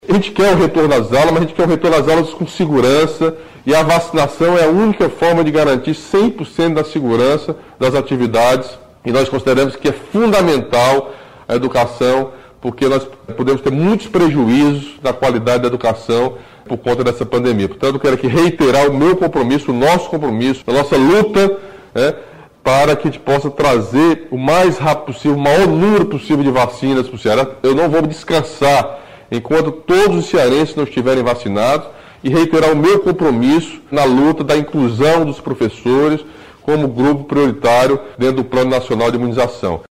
Camilo Santana lembrou que o Estado também luta para incluir os professores como grupo prioritário no Programa Nacional de Imunizações contra Covid-19, operacionalizado pelo Ministério da Saúde. Ele tratou do assunto diretamente com o ministro da Saúde, Marcelo Queiroga.